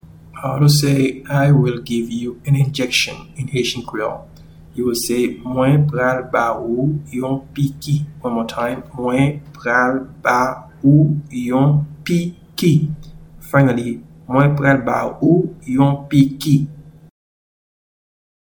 Pronunciation:
I-will-give-you-an-injection-in-Haitian-Creole-Mwen-pral-ba-ou-yon-piki.mp3